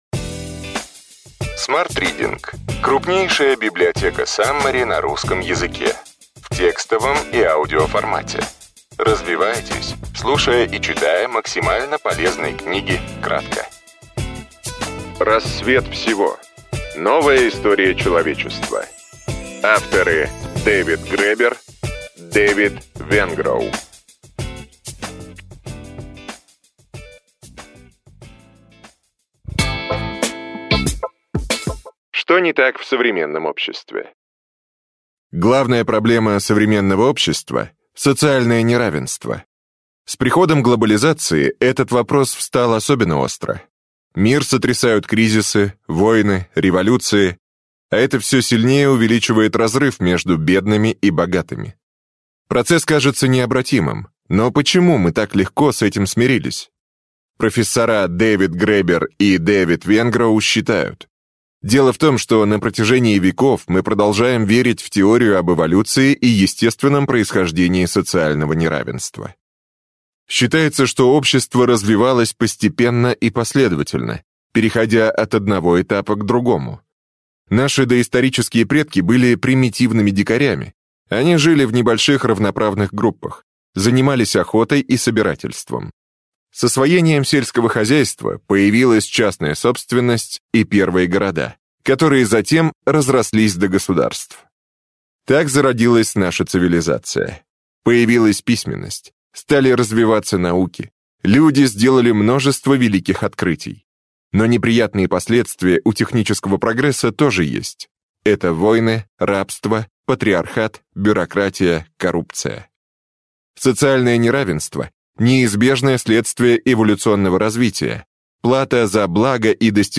Аудиокнига Рассвет всего. Новая история человечества.